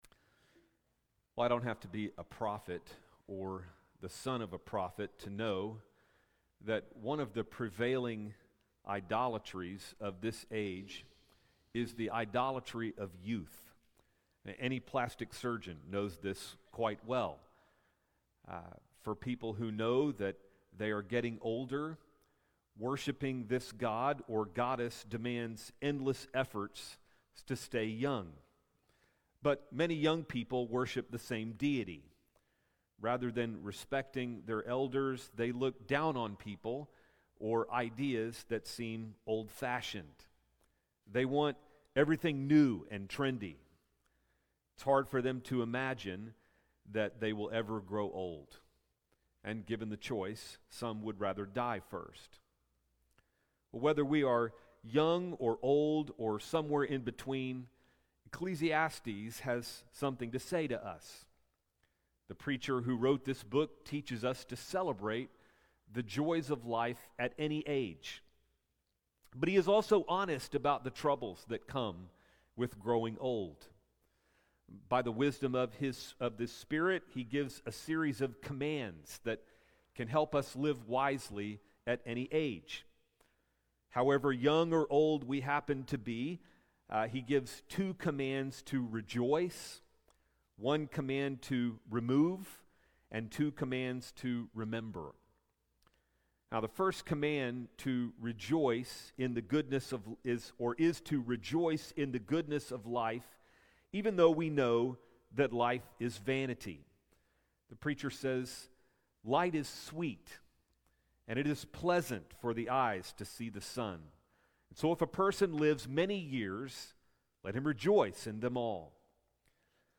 Sermon Series on the book of Ecclesiastes starting November 19, 2023.
FBC-Service-June-2nd.mp3